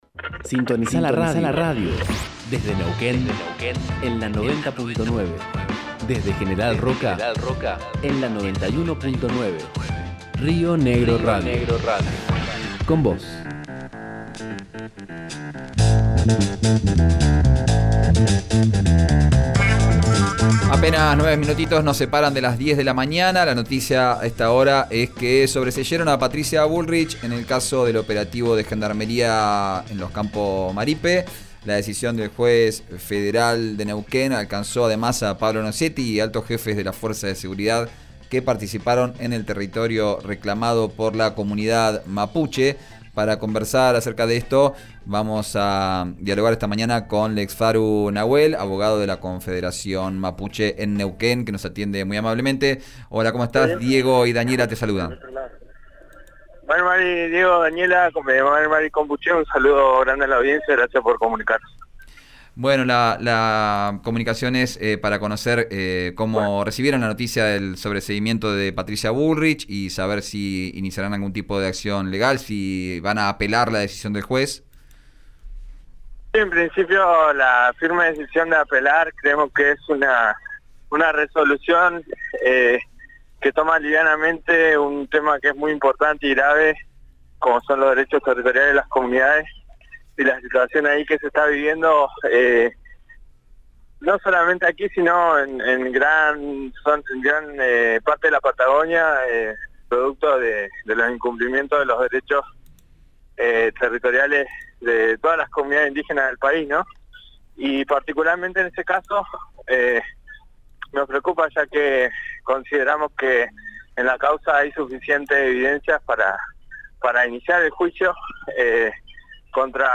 habló con RÍO NEGRO RADIO sobre la resolución del juez federal